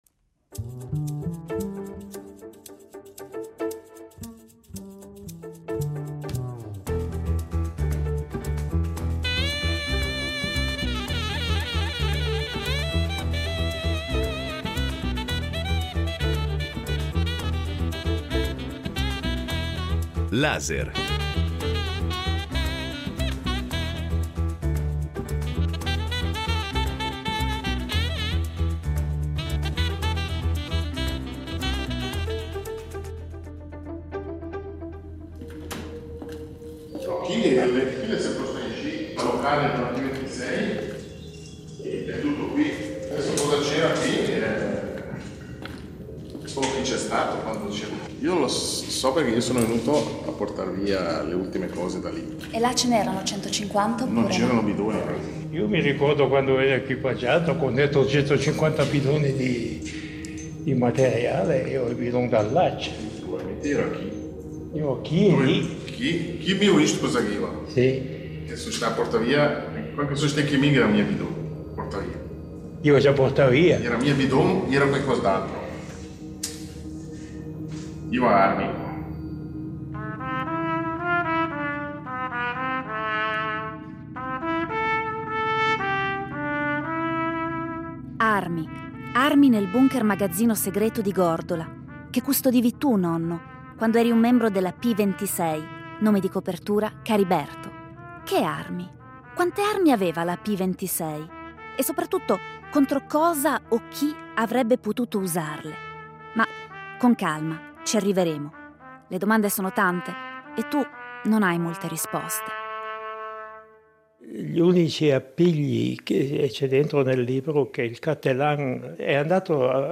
È la prima volta che un veterano ticinese della P26 si fa avanti e si racconta.